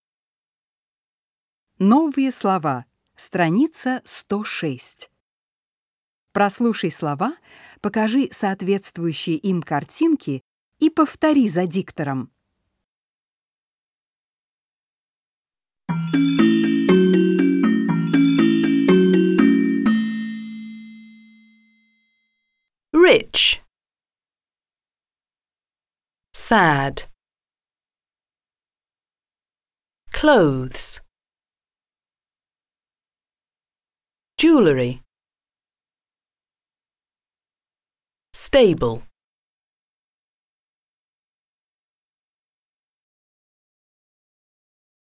Прослушай слова, покажи соответствующие им картинки и повтори слова за диктором.
09-Новые-слова-с.-106-.mp3